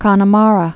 (kŏnə-märə)